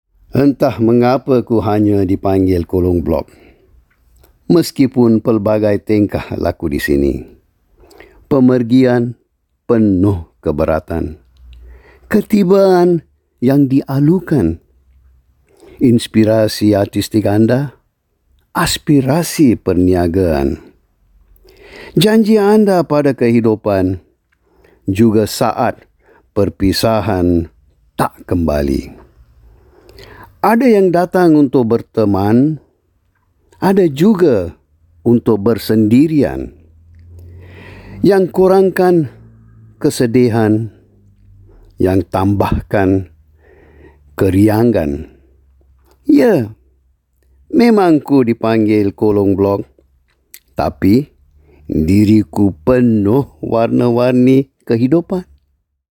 Voice Samples
male